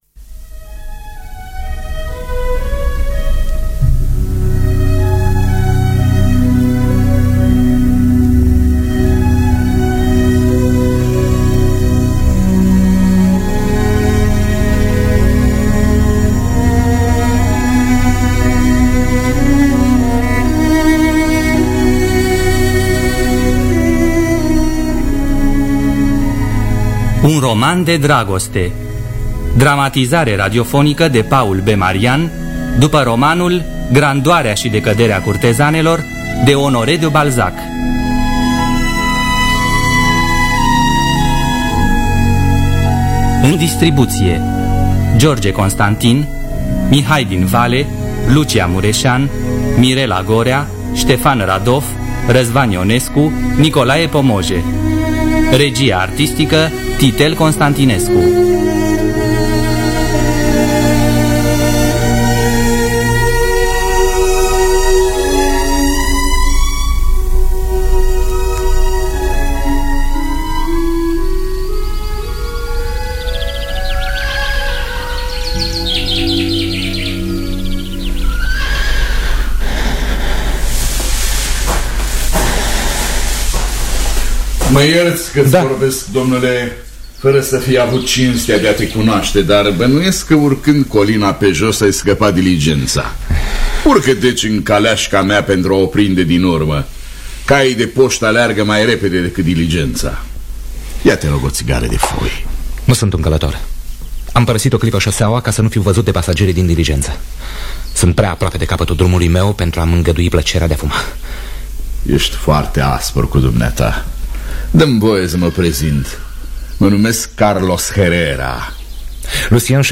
Un roman de dragoste sau Grandoarea și decăderea curtezanelor de Honoré de Balzac – Teatru Radiofonic Online